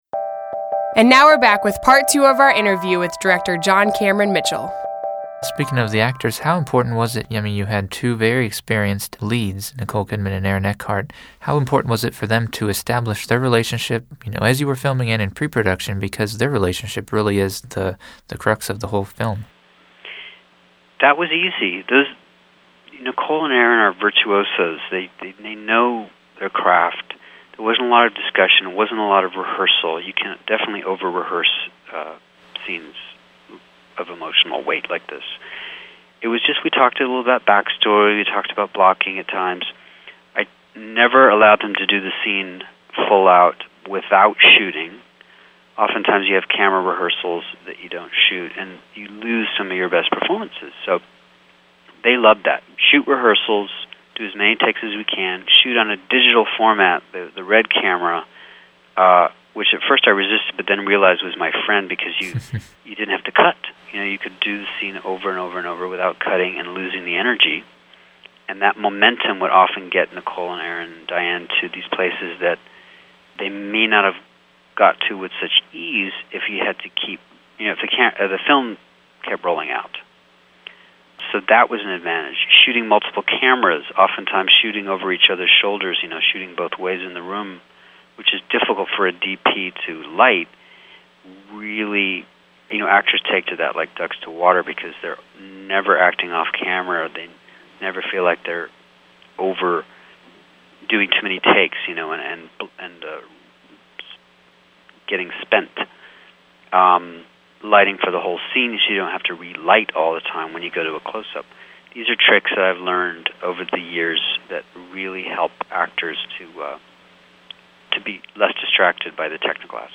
Filmmaker & Talent Interviews - Heartland Film
Heartland_Podcast_Interview_13_John_Cameron_Mitchell_Part_2.mp3